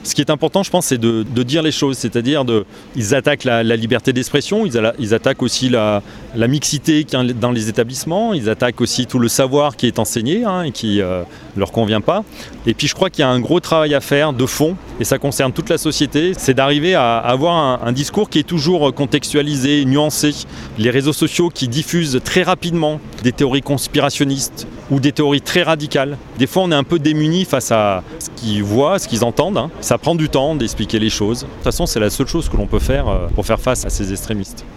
A Annecy, parmi les élus qui se sont mobilisés : Fabien Géry. Il est maire-adjoint à la culture.